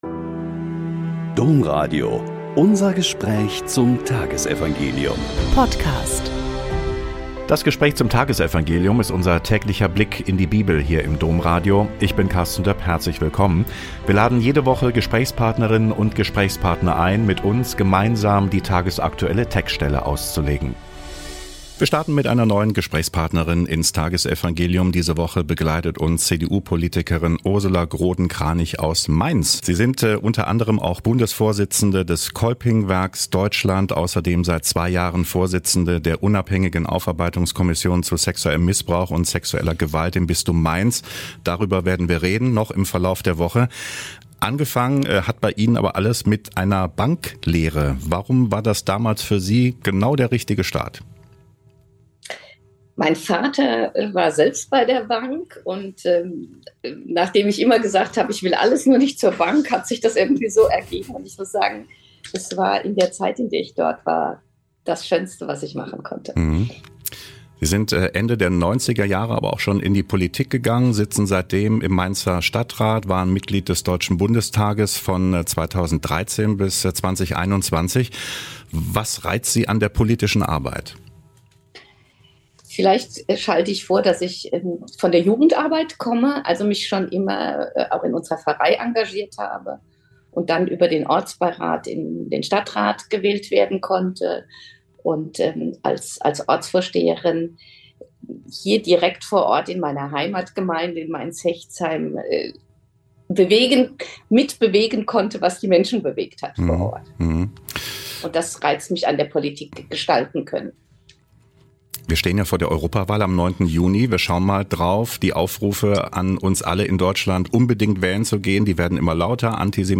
Joh 16,29-33 - Gespräch mit Ursula Groden-Kranich